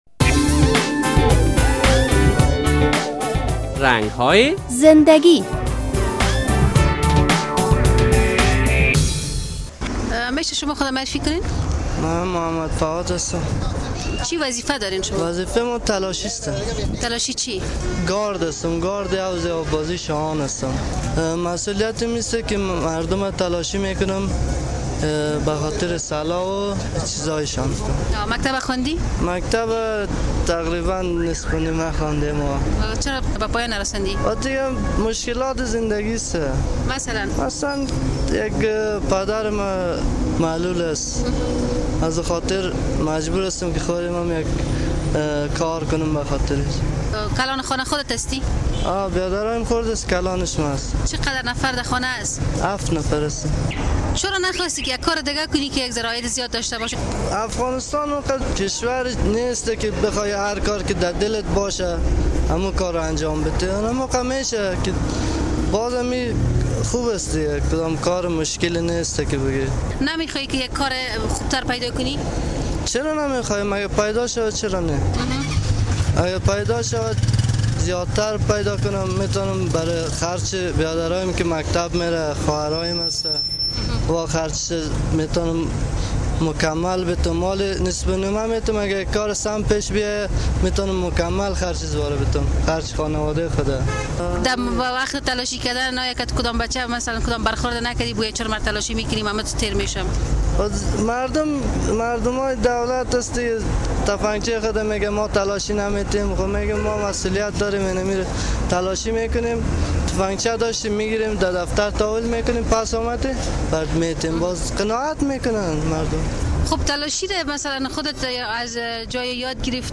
در این برنامهء رنگ های زنده گی با یک تن از افراد که وظیفهء تلاشی را دارد، صحبت شده است.